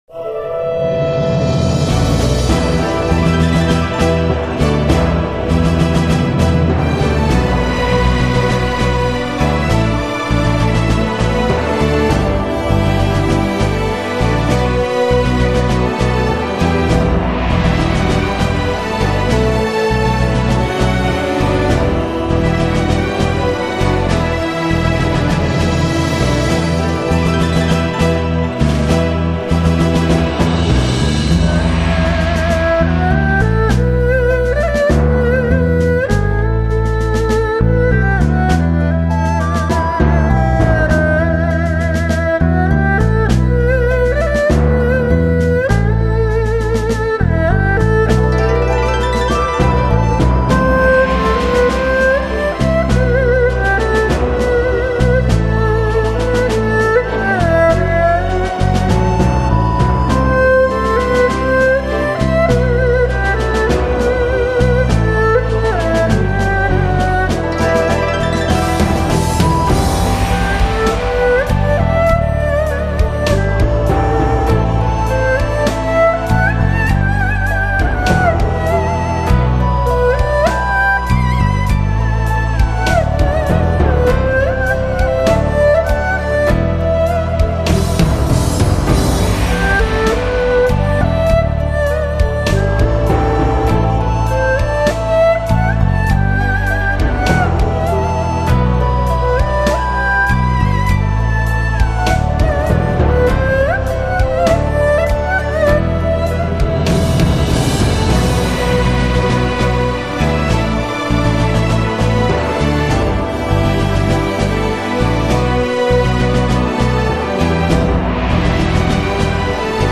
二胡